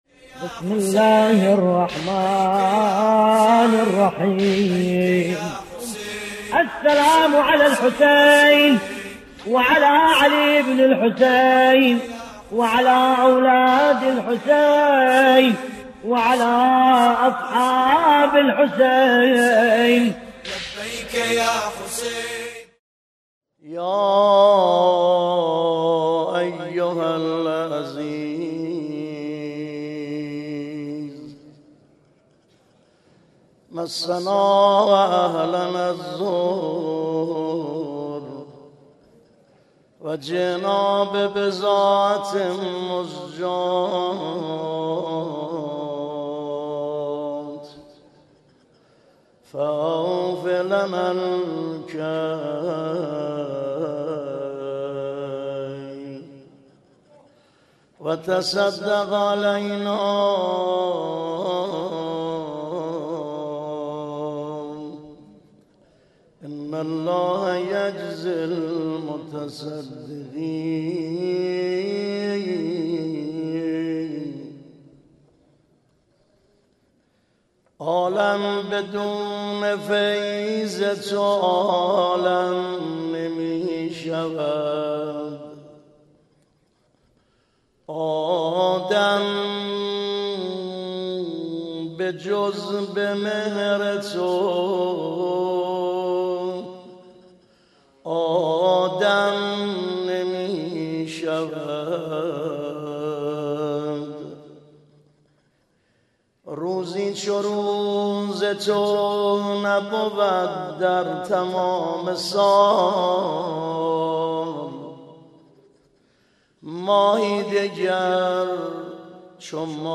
مراسم شهادت حضرت رقیه علیهاالسلام صفر1438- در درس خارج فقه معظم له | سایت رسمی دفتر حضرت آيت الله العظمى وحيد خراسانى